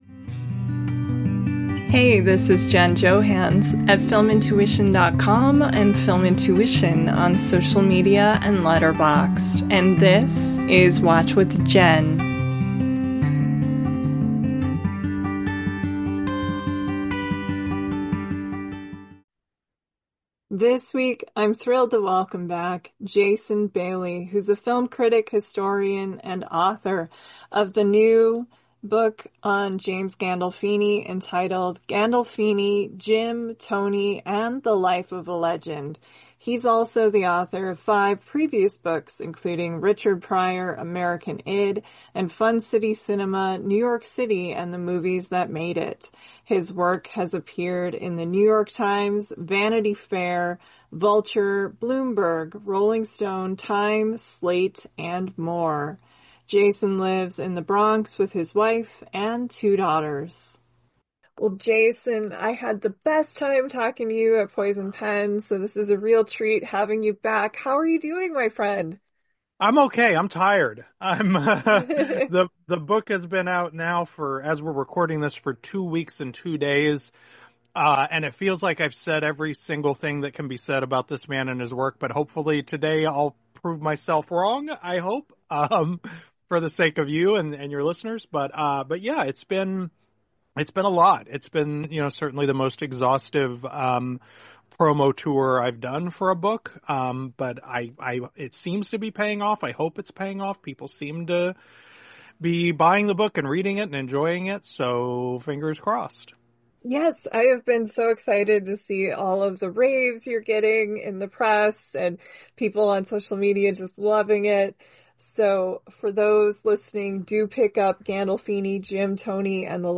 Theme Music: Solo Acoustic Guitar